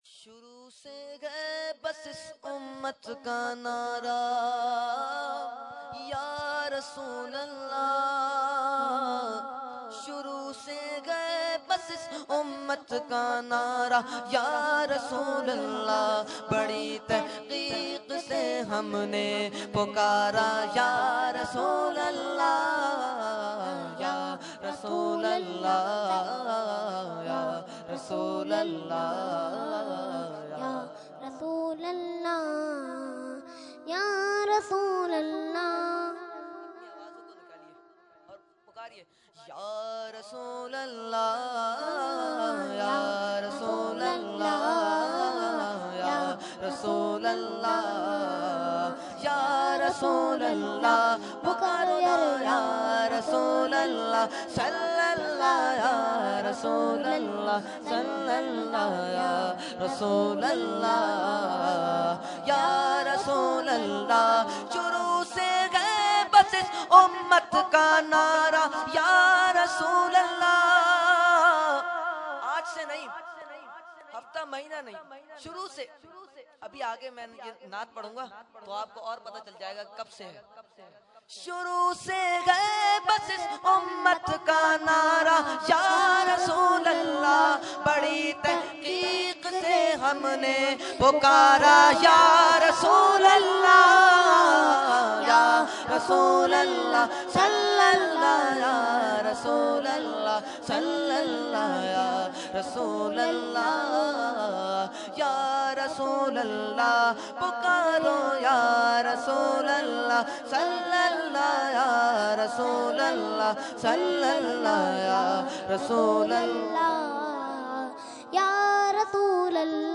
Category : Naat | Language : UrduEvent : Mehfil 11veen North Nazimabad 31 March 2014